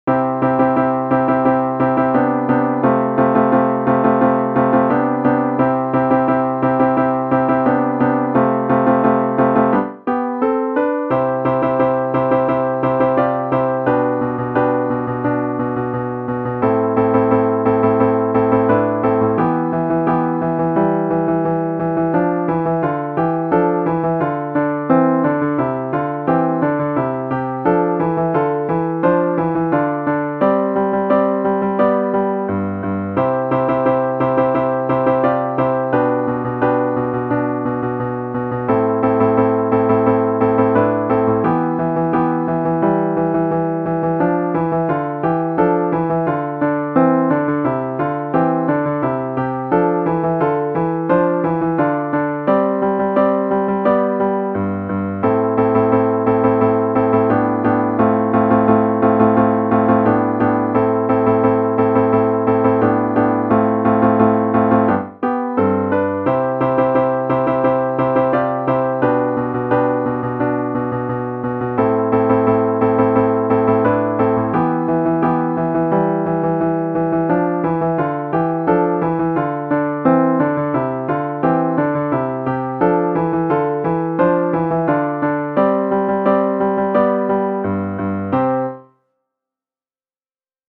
Für 4 Gitarren
Pop/Rock/Elektronik
Ensemblemusik
Quartett
Gitarre (4)